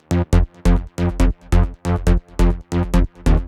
VTS1 Another Day Kit Bassline